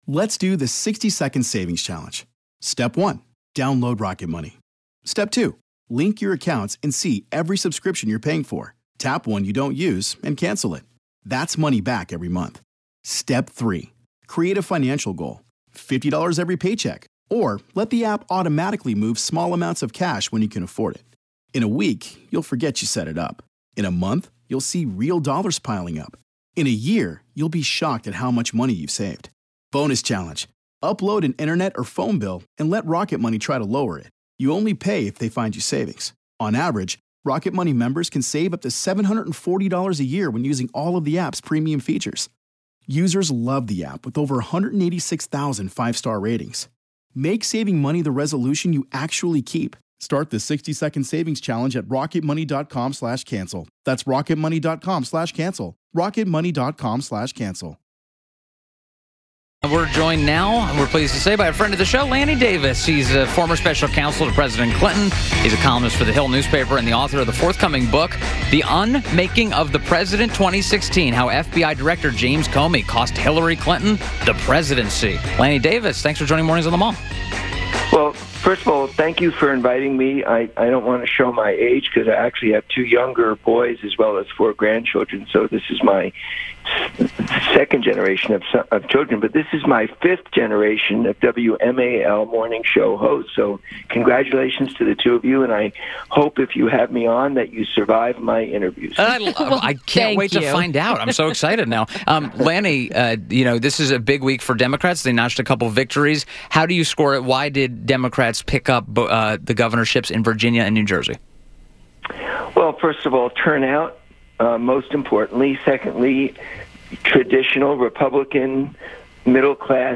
WMAL Interview - LANNY DAVIS - 11.10.17